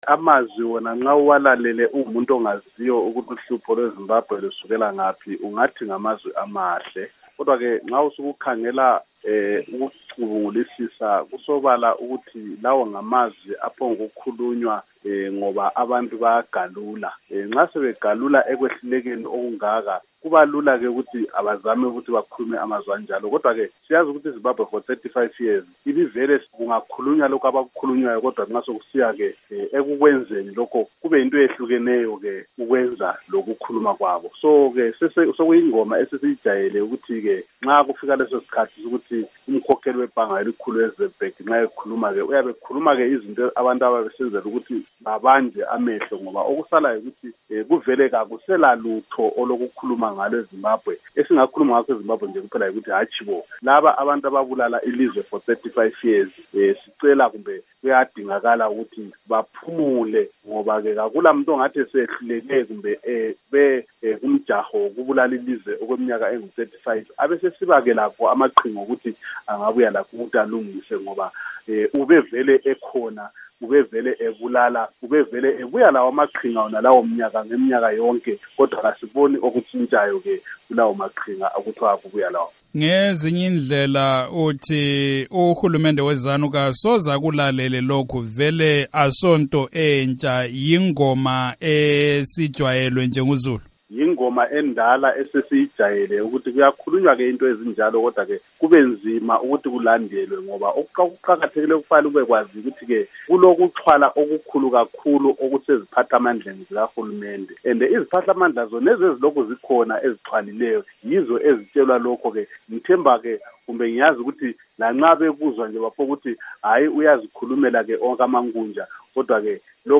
Ingxoxo loMnu. Abednigo Bhebhe